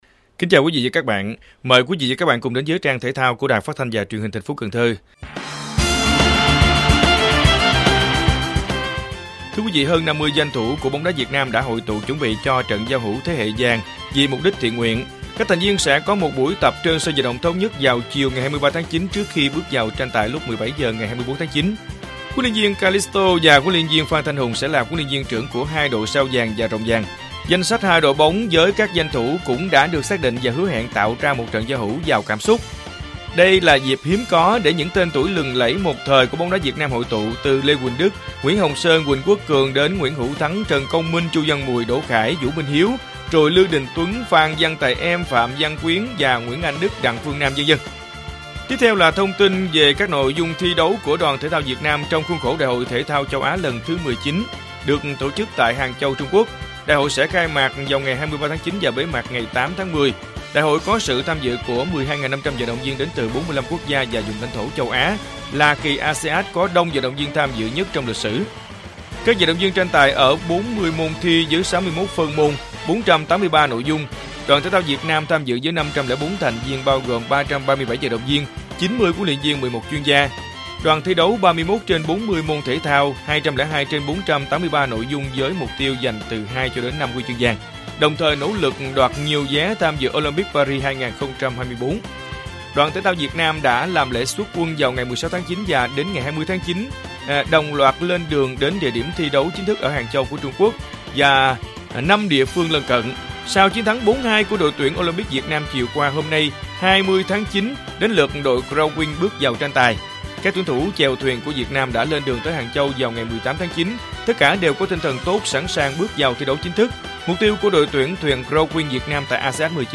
Bản tin thể thao 20/9/2023